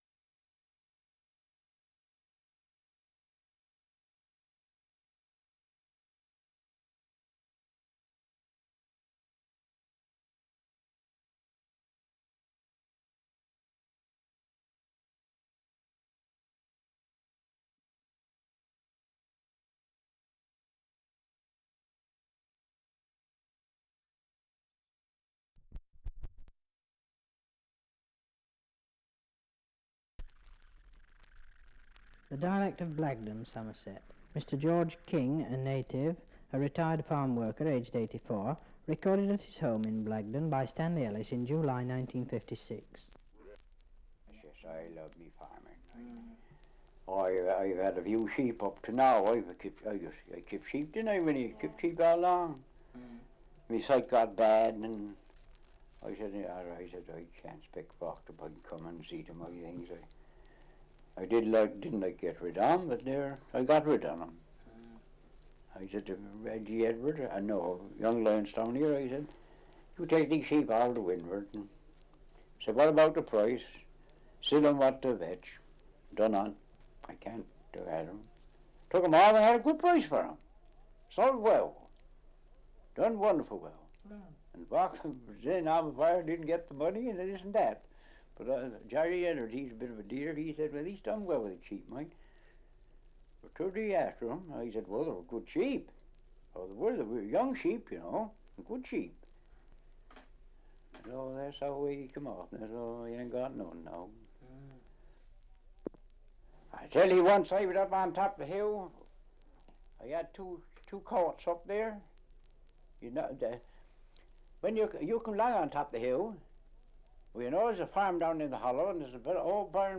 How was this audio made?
Survey of English Dialects recording in Blagdon, Somerset 78 r.p.m., cellulose nitrate on aluminium